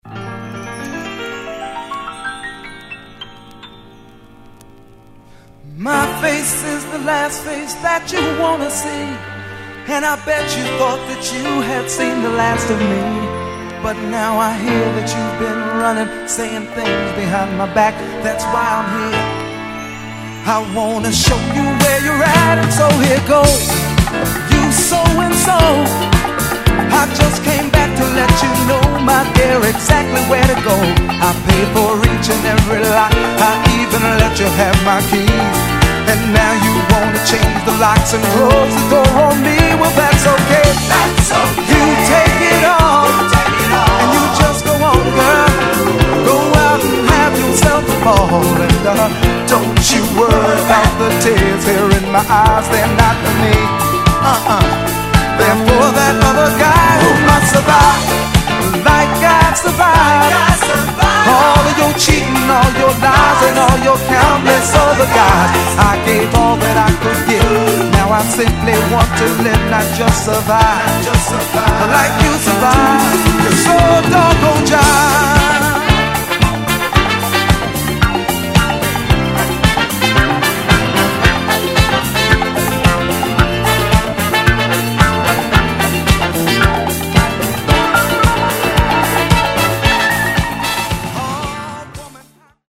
・ DISCO 70's 12'